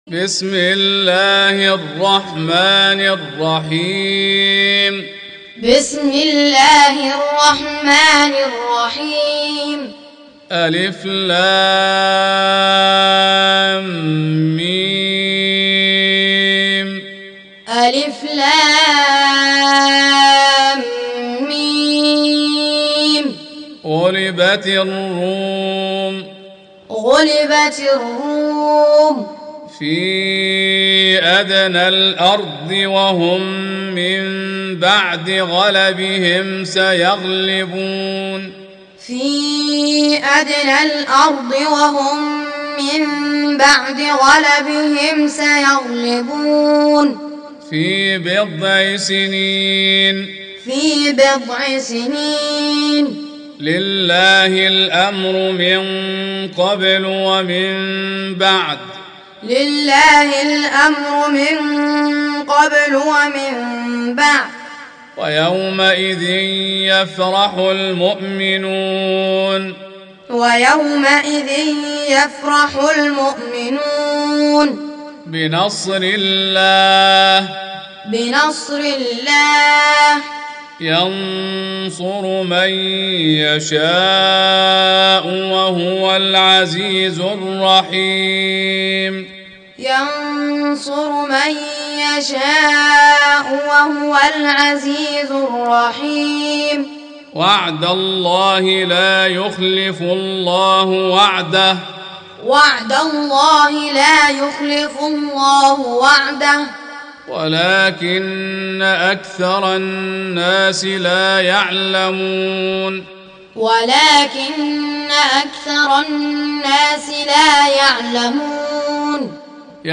Tutorial Recitation
Surah Repeating تكرار السورة Download Surah حمّل السورة Reciting Muallamah Tutorial Audio for 30.